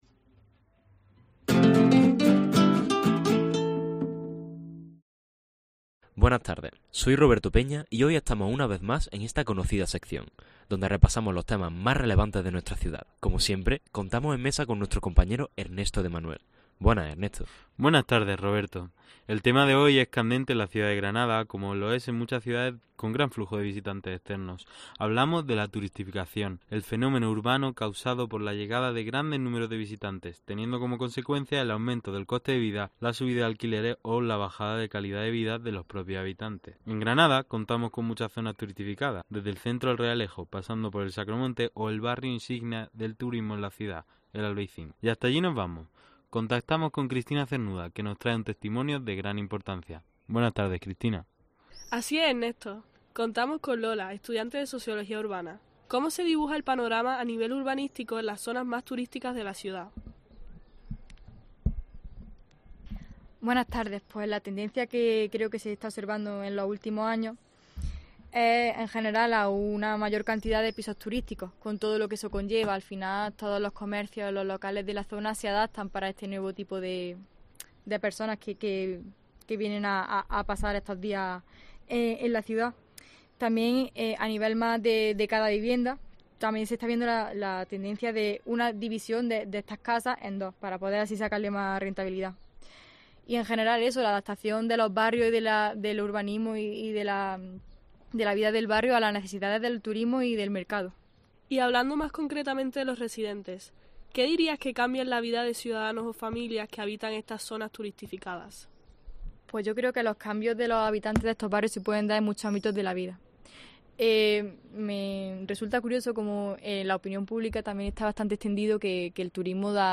Reportaje realizado por alumnos de la UGR